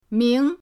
ming2.mp3